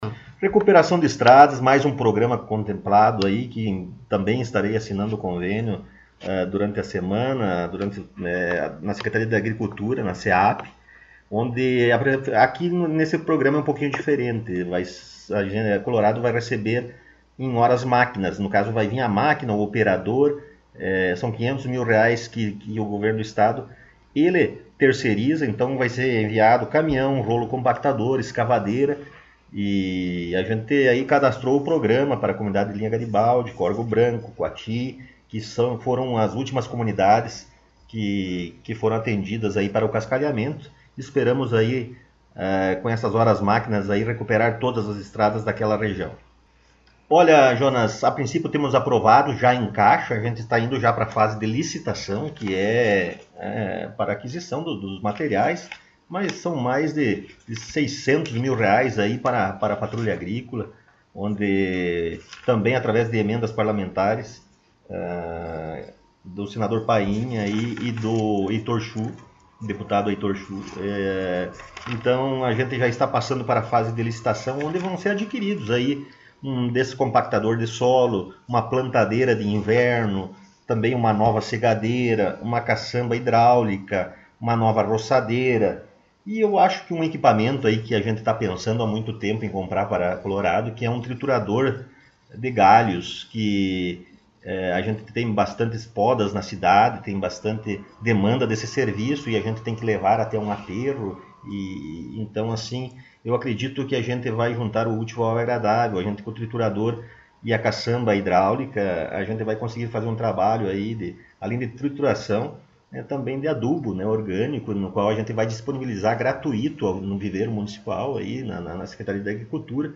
Prefeito Rodrigo Sartori concedeu entrevista